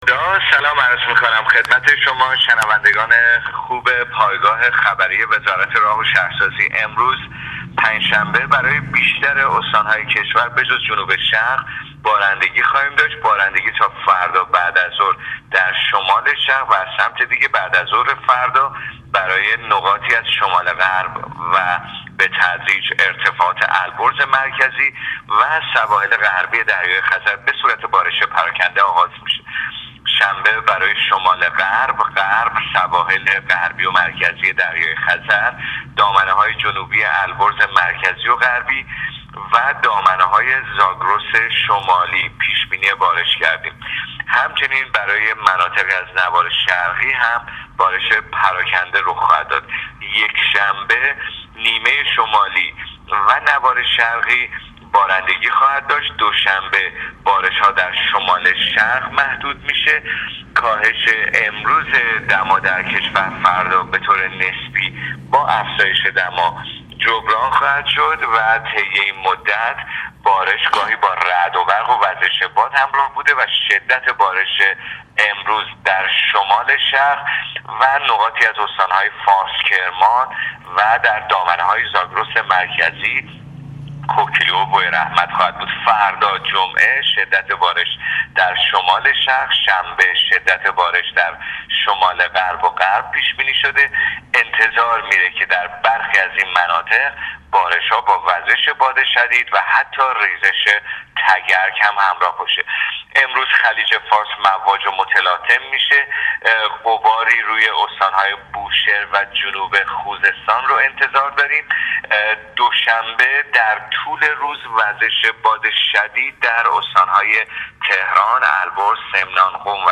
گزارش رادیو اینترنتی از آخرین وضعیت آب و هوای۱۱ اردیبشهت ۱۳۹۹